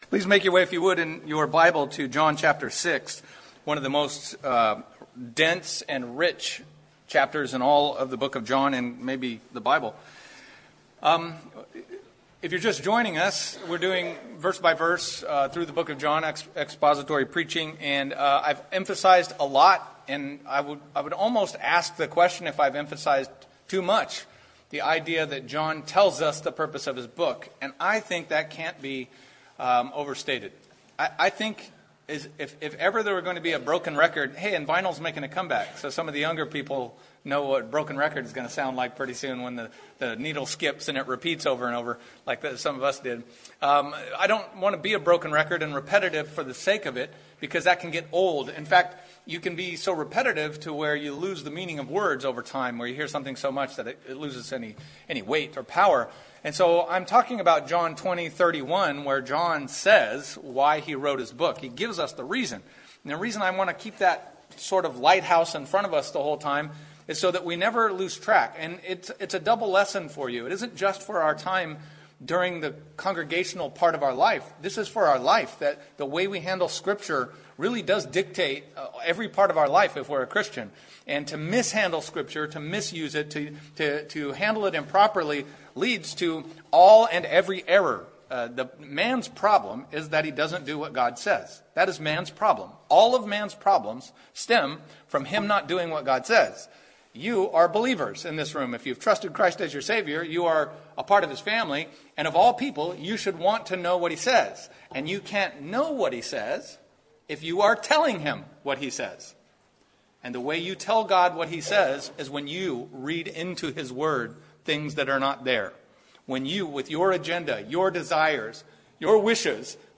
A message from the series "The Gospel of John."